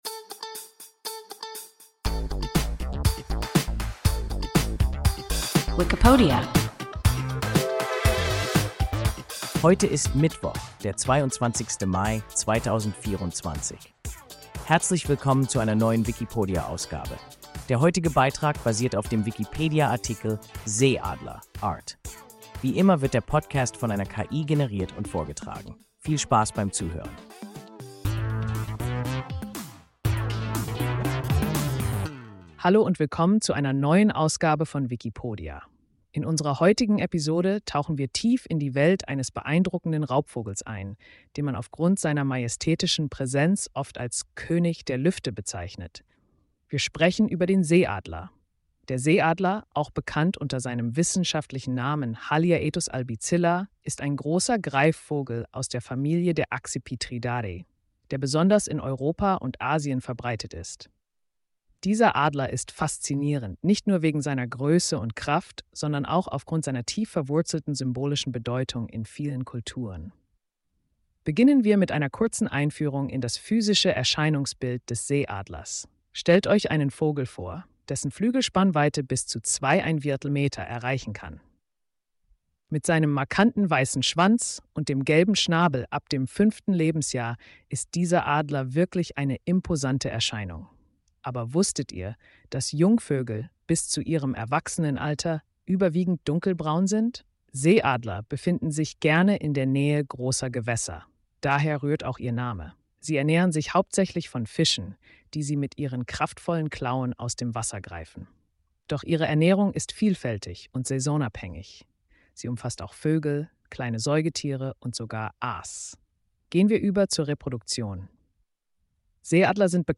Seeadler (Art) – WIKIPODIA – ein KI Podcast